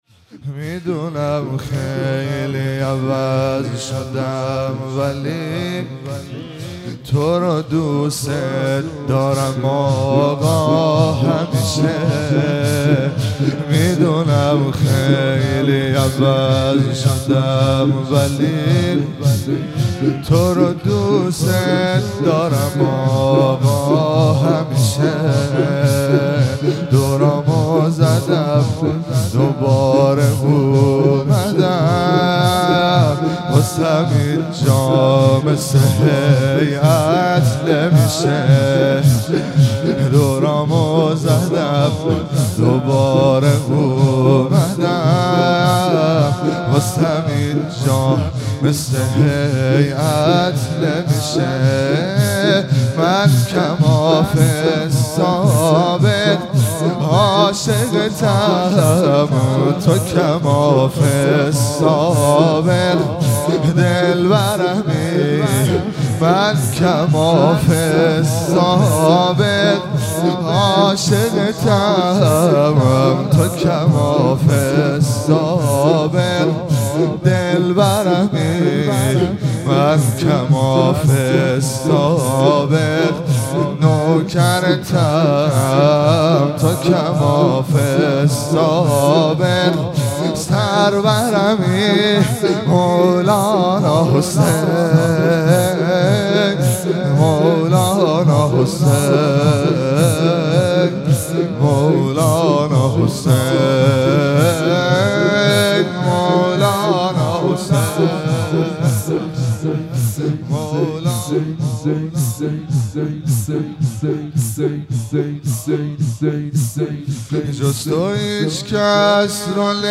مراسم مناجات خوانی شب چهارم ماه رمضان 1444
سینه زنی- میدونم خیلی عوض شدم ولی، تورو دوست دارم آقا